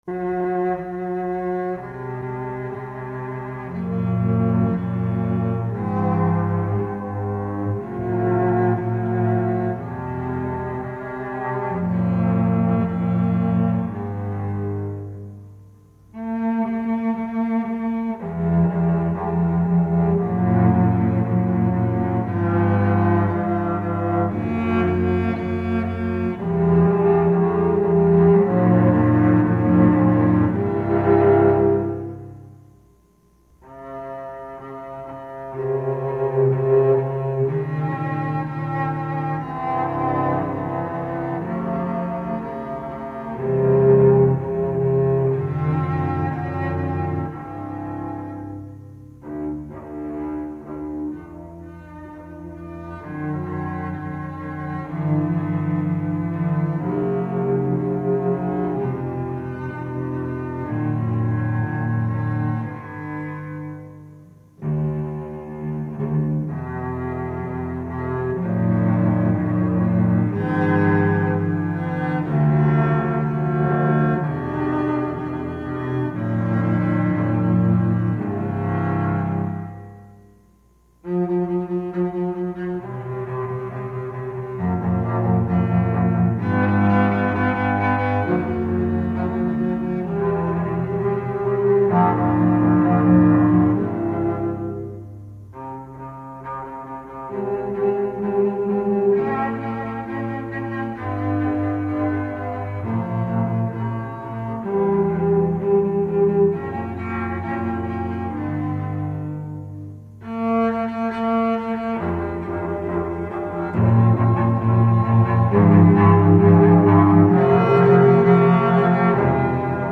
Instrumental
Canons à 2, 3 et 4 voix